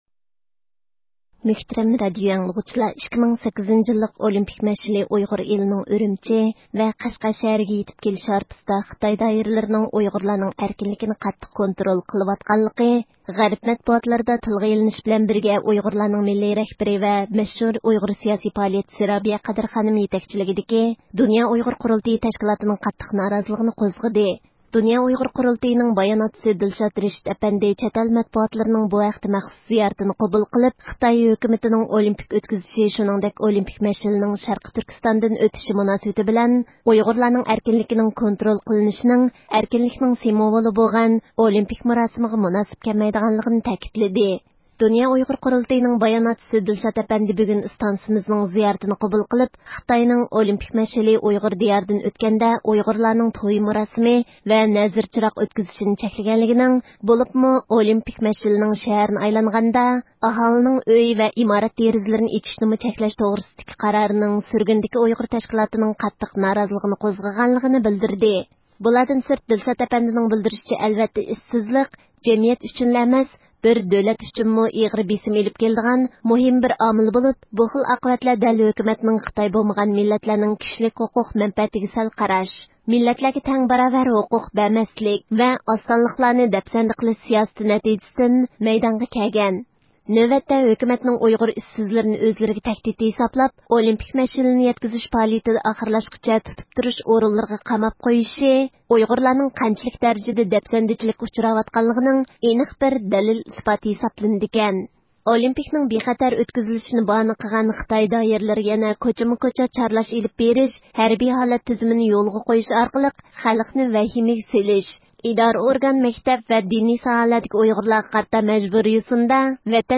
مۇخبىرىمىز بۇ ھەقتە مەلۇمات ئىگىلەش ئۈچۈن ئۈرۈمچىدىكى نەنگۈەن ساقچىخانىسى ۋە بىر قانچە ئاممىۋى ئورۇنلارغا تېلېفۇن ئۇردى.
ئۇيغۇر ساقچى، شەھەردە يولغا قويۇلۇۋاتقان چارلاش ھەرىكەتلىرىنىڭ راستلىقىنى رەت قىلدى، بىز بۇ ھەقتە تېخىمۇ ئىچكىرىلەپ مەلۇمات ئېلىش ئۈچۈن شەھەر ئاھالىسى ئارىسىدىكى بىر قانچە كىشىگە تېلېفۇن ئۇردۇق.
ئەركىن ئاسىيا رادىئوسىدىن تېلېفۇن ئۇرۇۋاتقانلىقىمىزدىن خەۋەر تاپقان بىر ئايال ھۆكۈمەتنىڭ چەتئەل مەتبۇئاتلىرىنىڭ زىيارىتىنى قوبۇل قىلماسلىق ھەققىدىكى بۇيرۇقى تۈپەيلىدىن بولۇشى كېرەك، ناھايىتى ھودۇقۇپ كەتتى ۋە بىزگە قايىل قىلارلىق جاۋاب بەرمىدى.